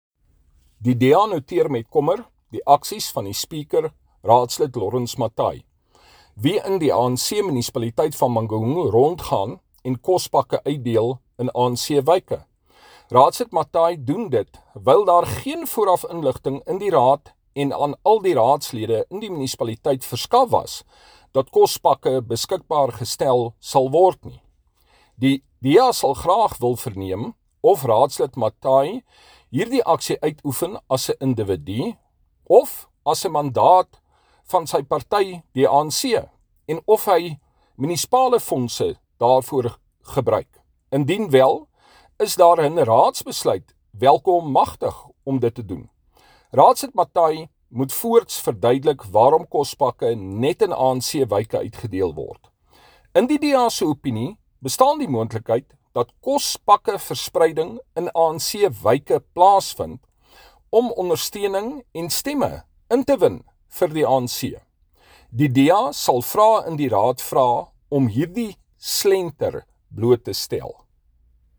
Afrikaans by Cllr Hardie Viviers.
Afr-voice-Hardie.mp3